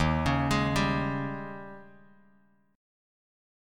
D#11 chord